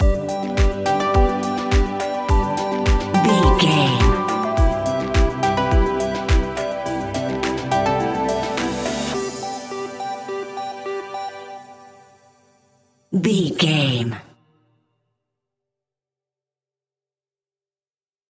Ionian/Major
groovy
uplifting
energetic
repetitive
synthesiser
drums
electric piano
strings
electronic
drum machine
synth bass